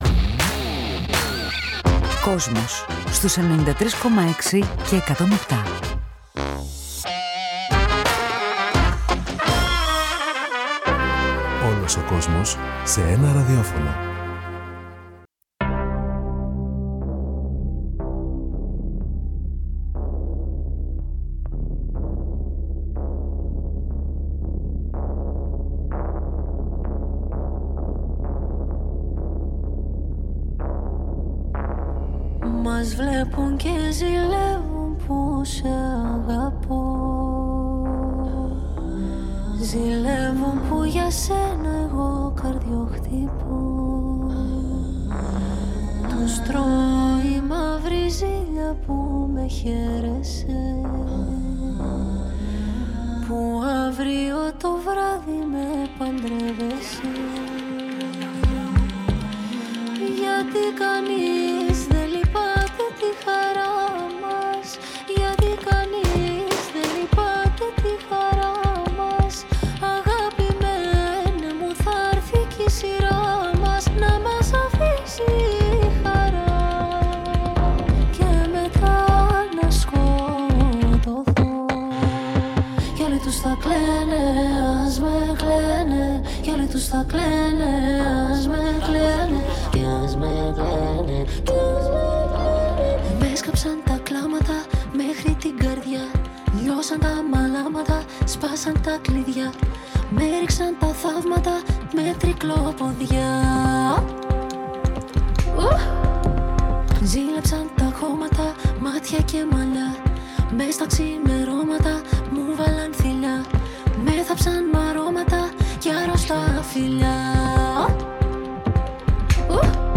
Η Μαρίνα Σάττι στο studio του Kosmos- Συνέντευξη | 09.03.2023